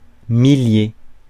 Prononciation
Synonymes chiliade Prononciation France: IPA: [mi.lje] Le mot recherché trouvé avec ces langues de source: français Les traductions n’ont pas été trouvées pour la langue de destination choisie.